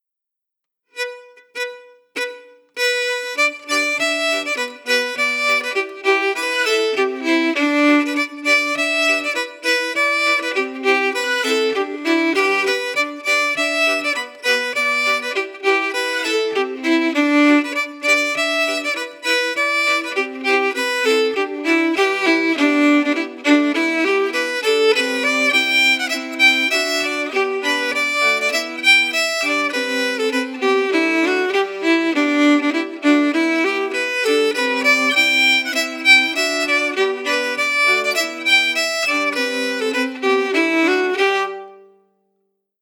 Key: G
Form: Reel
Melody emphasis